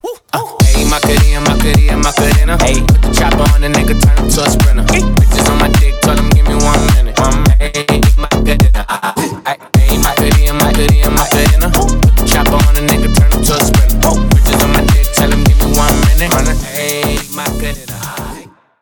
Ремикс
весёлые # клубные